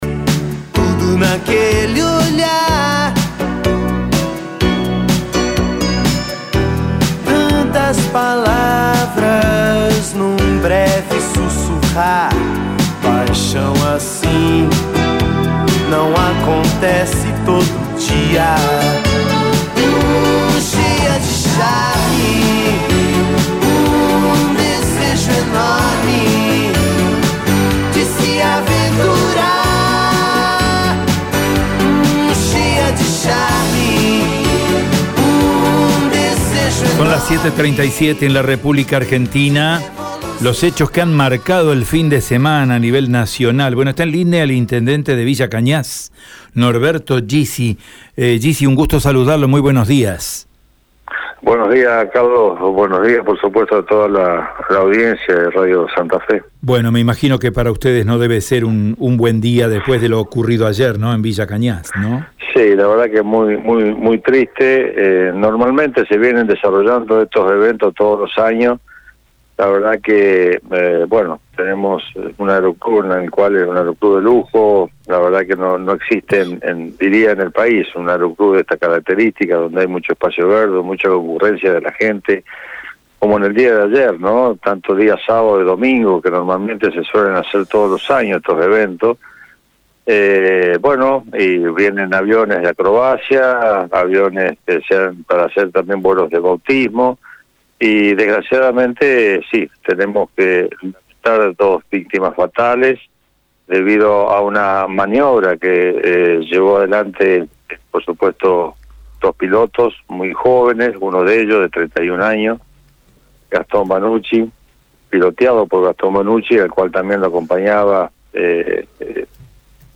Escucha la palabra del intendente de Villa Cañas en Radio EME: